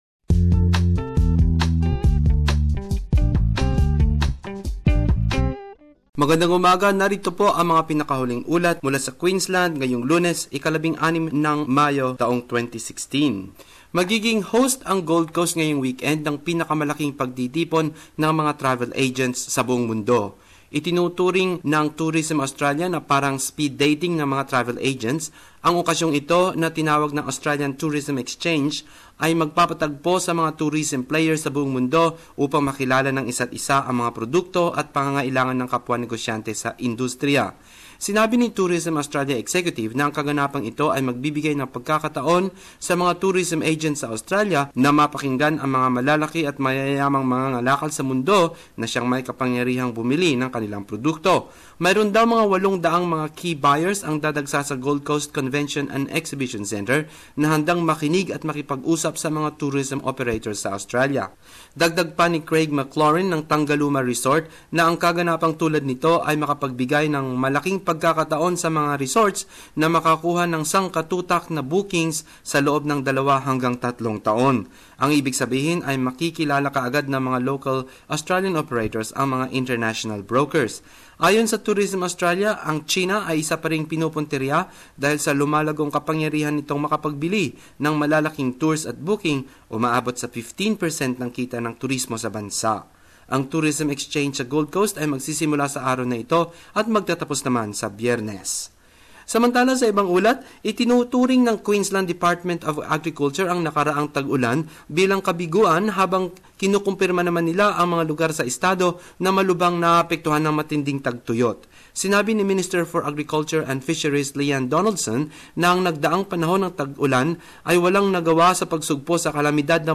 Queensland News.